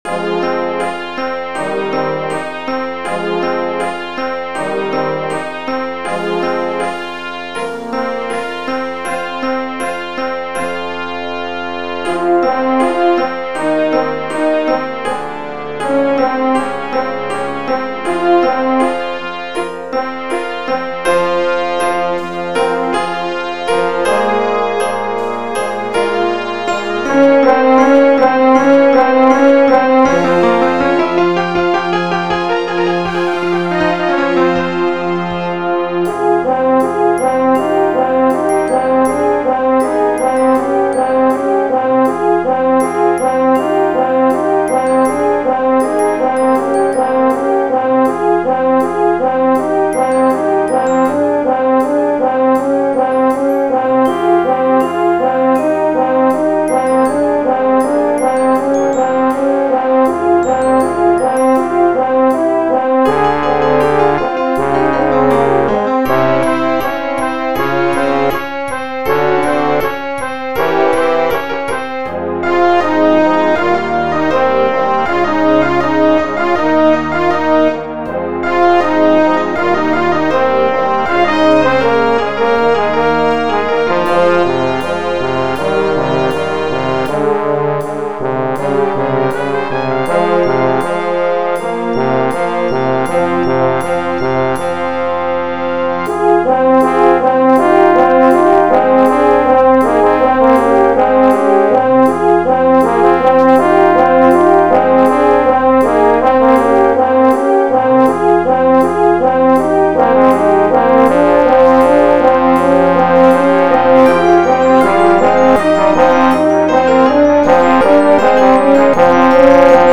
Brassband
mp3 (elektronisch generierte Aufnahme)